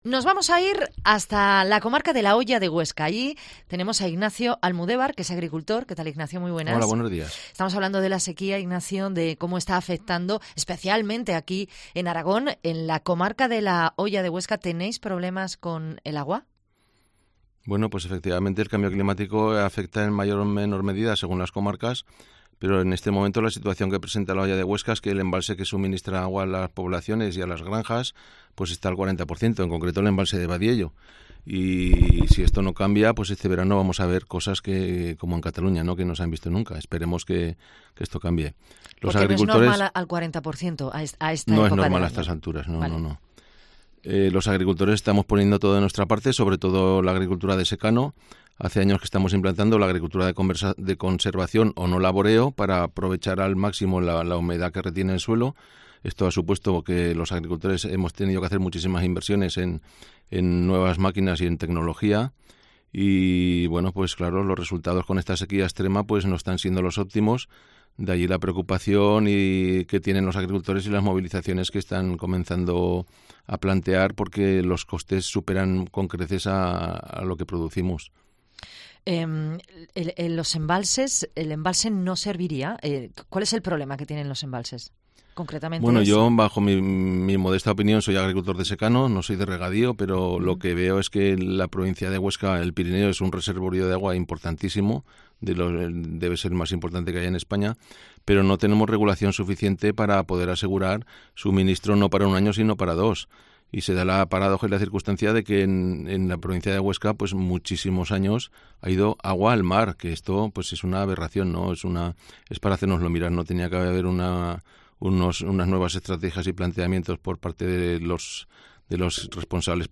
Entrevista a tres agricultores de Zaragoza, Huesca y Teruel afectados por la sequía